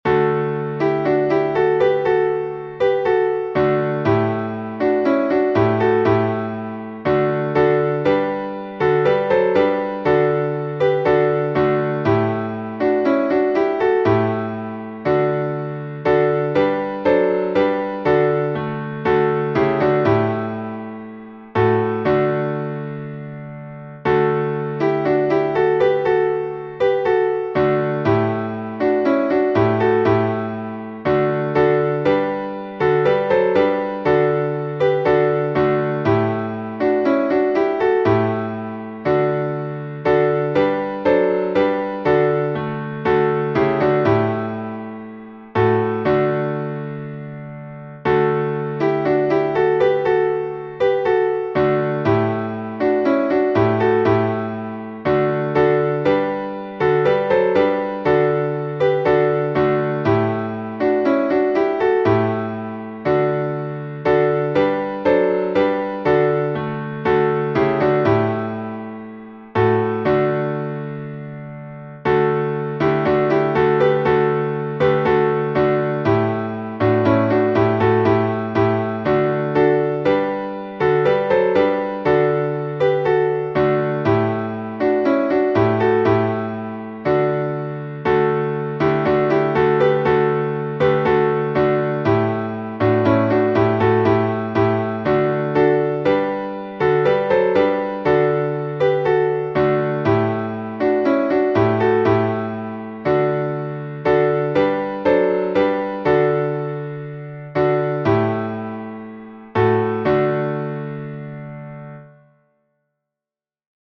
Болгарский распев